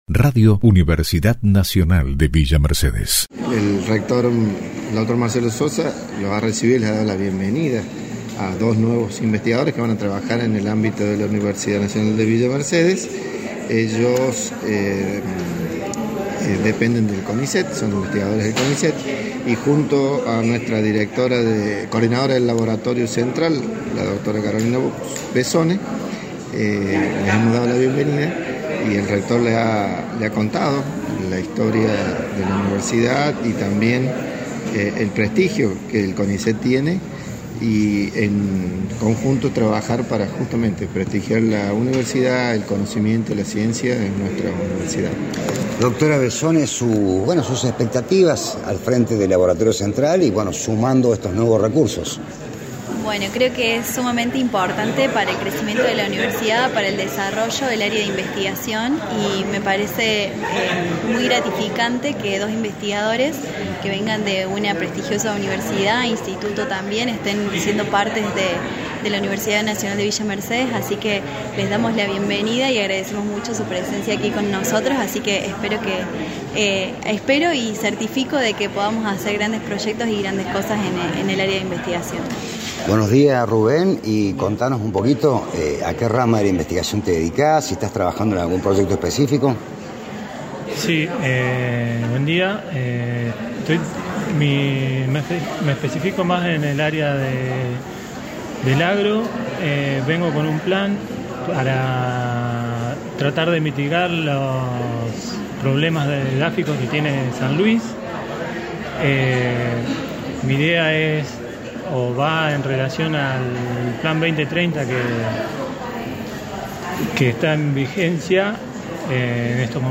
Entrevista a funcionarios e investigadores